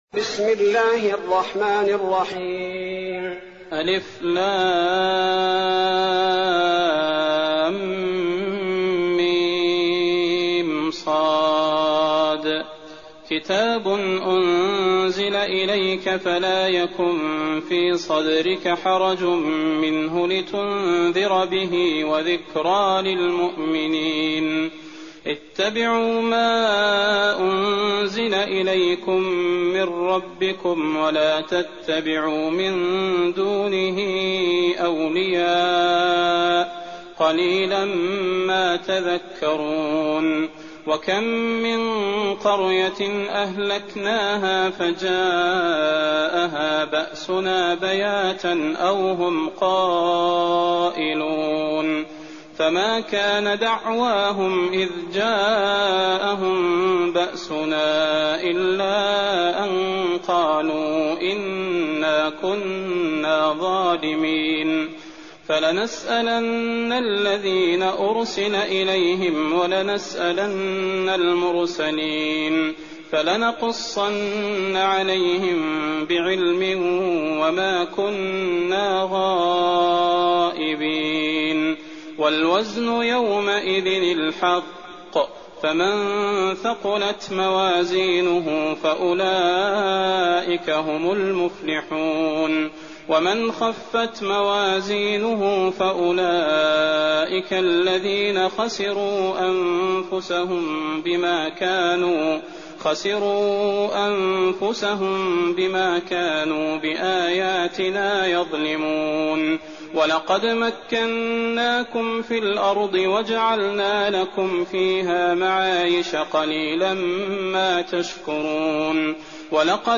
تراويح الليلة الثامنة رمضان 1419هـ من سورة الأعراف (1-87) Taraweeh 8th night Ramadan 1419H from Surah Al-A’raf > تراويح الحرم النبوي عام 1419 🕌 > التراويح - تلاوات الحرمين